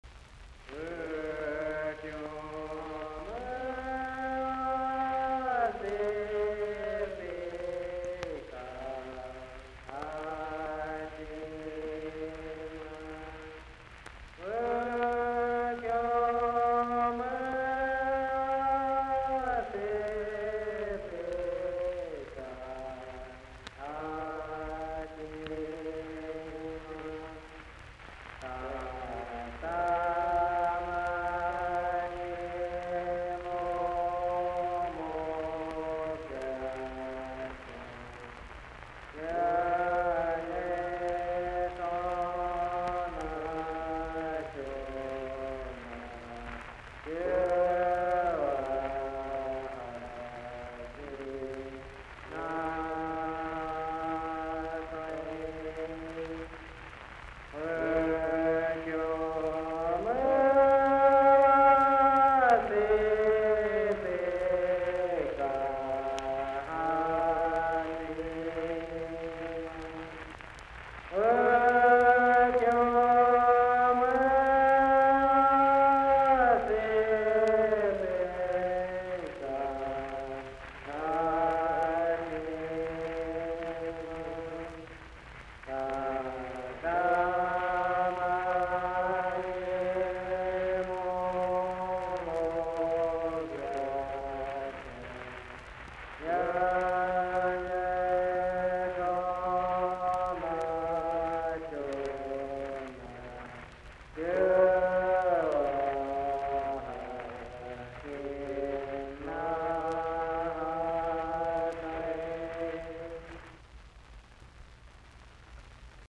Recorded in Indian communities by Willard Rhodes, with the cooperation of the United States Office of Indian Affairs.